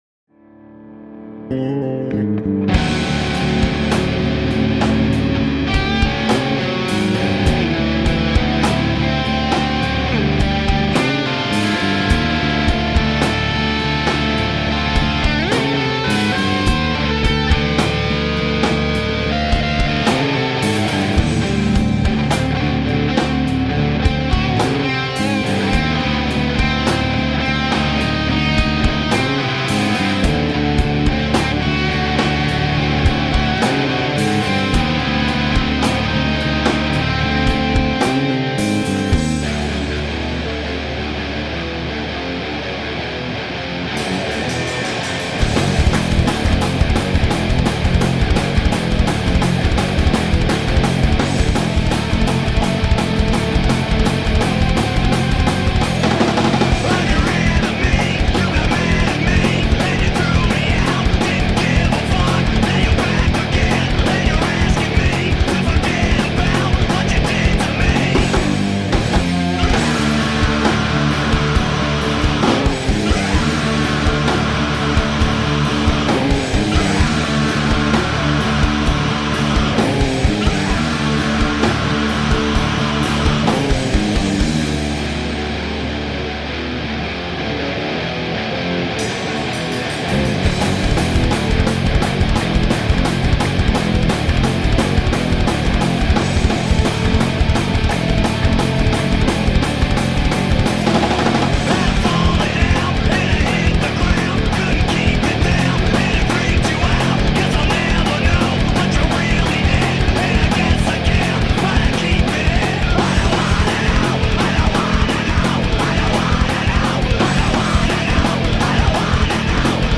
Heavy modern rock..
darkly precise yet deeply cathartic hard rock/metal
Tagged as: Hard Rock, Punk, High Energy Rock and Roll